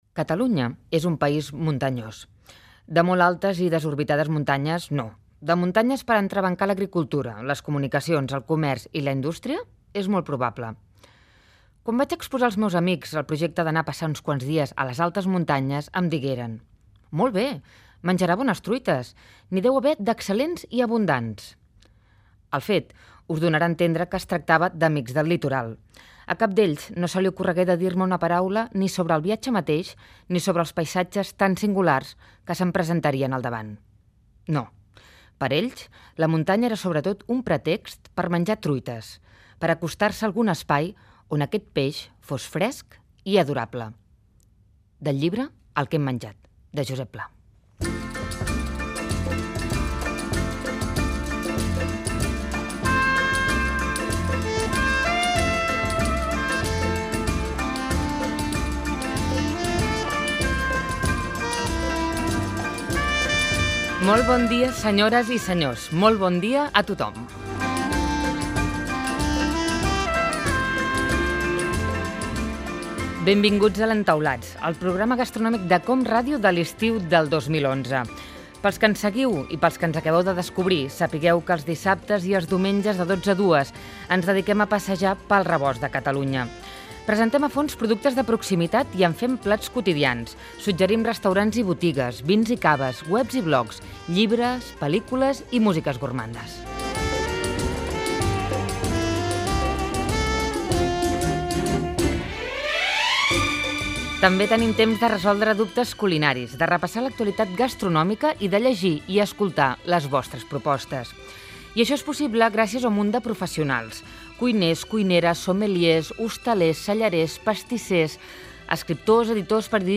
Comentari inicial sobre els pobles de munyanya, extret d'un llibre de Josep Pla, salutació, crèdits i sumari del programa. Dedicat a la truita de muntanya i els paisatges del Pallars Sobirà.
Divulgació